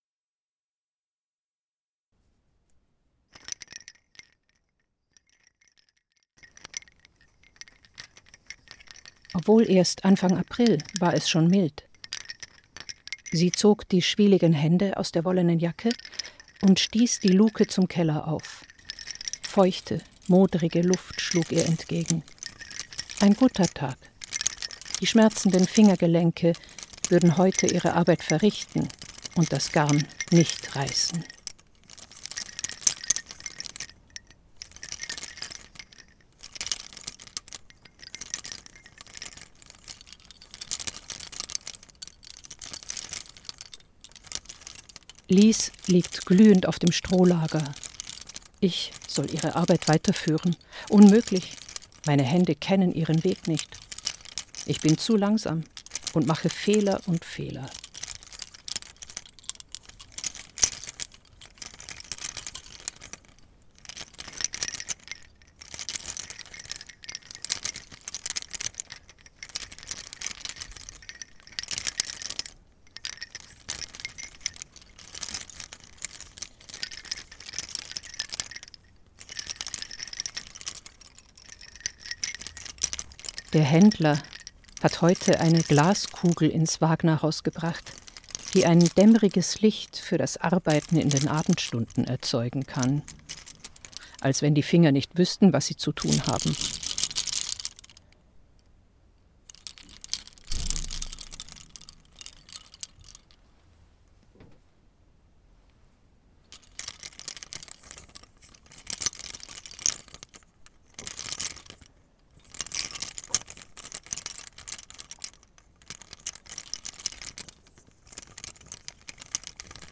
Klang-Installation aus Lautsprecherkabeln , Klöppelgeräusche und versprachlichten Texten